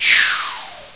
slash01.wav